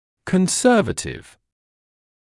[kən’sɜːvətɪv][кэн’сёːвэтив]консервативный (в т.ч. о лечении)